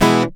OVATION E-.1.wav